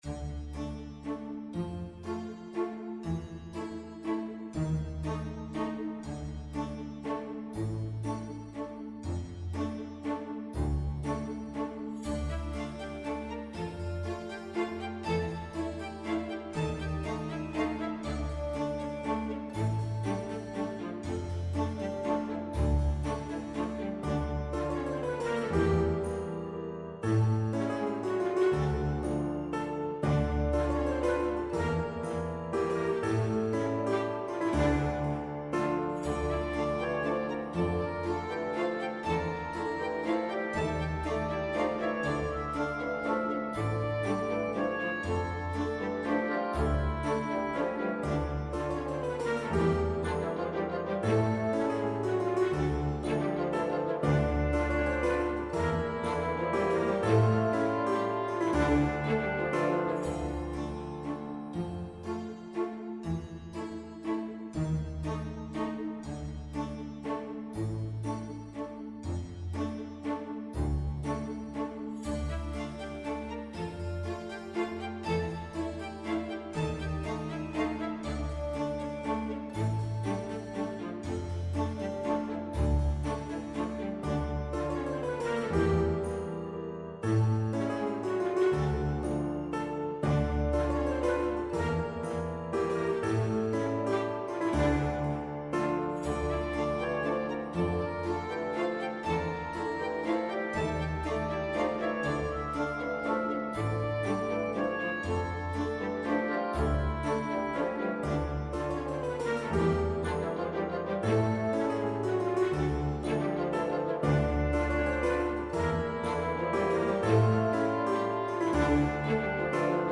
This one is really melodious!